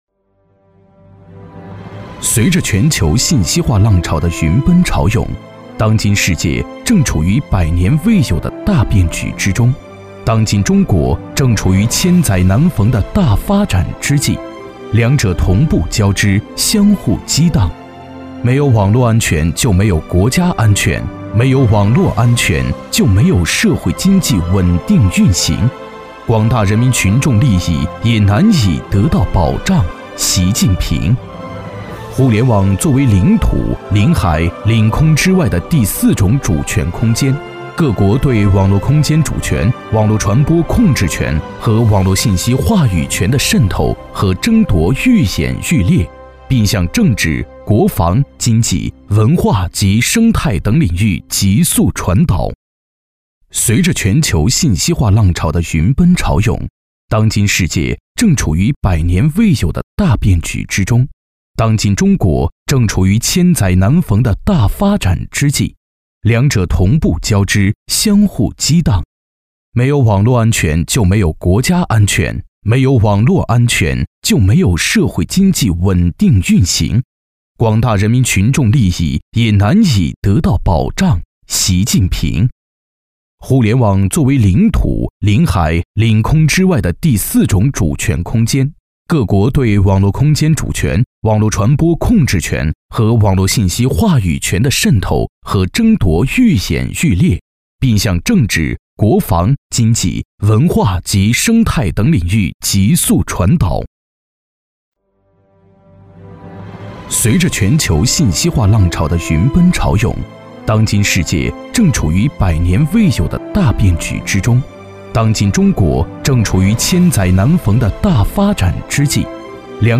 国语青年积极向上 、时尚活力 、素人 、脱口秀 、男飞碟说/MG 、100元/分钟男B073 国语 男声 病毒飞碟说-MG动画 女神 贱兮兮 欢快 积极向上|时尚活力|素人|脱口秀